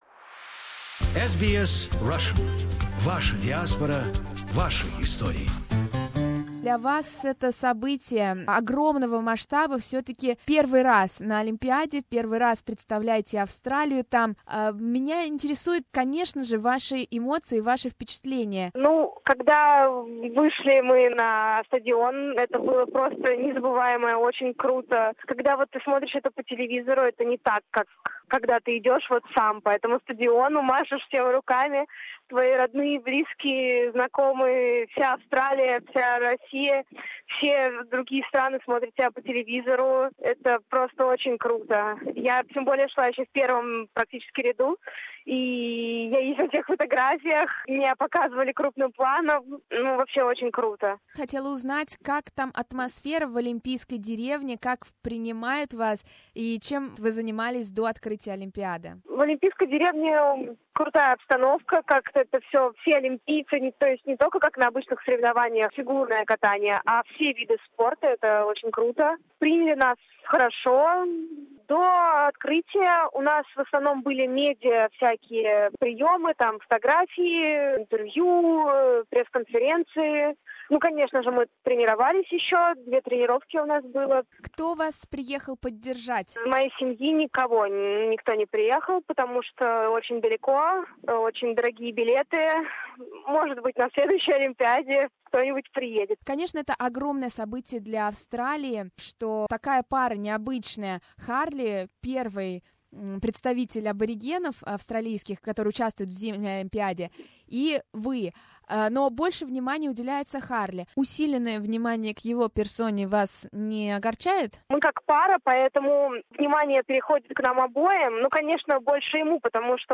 Last October the pair became the first Australians to win Junior Grand Prix championship. We spoke with Katia from Olympic Village in Gangneung.